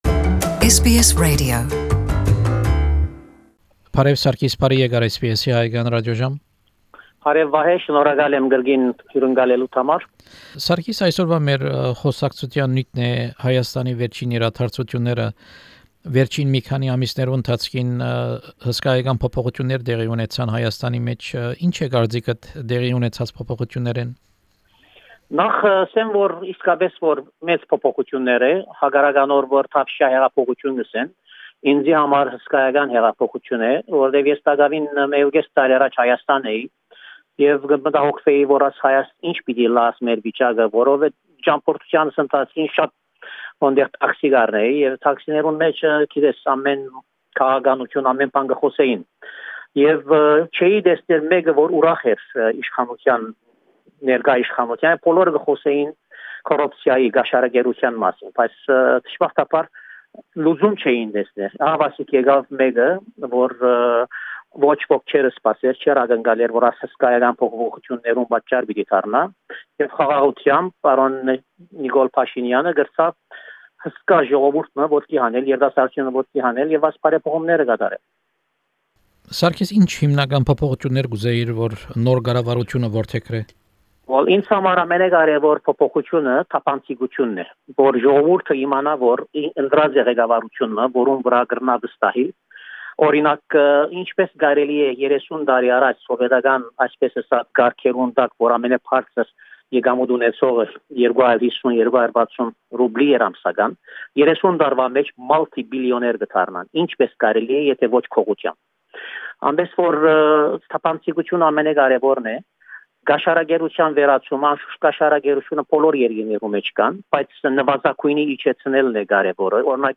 Հարցազրոյց Ռայտ քաղաքապետարանի խորհուրդի անդամ և Ռայտ Բազմամշակութային Կեդրոնի նախագահ Սարգիս Ետելեանի հետ: Հարցազրոյցի գլխաւոր նիւթն է Հայաստանի մէջ տեղի ունեցող դրական զարգացումները, Սփիւռք-Հայաստան յարաբերութիւններ և ինչպէս աւստրալահայեր կրնան նեցուկ կանգնիլ Հայաստանի: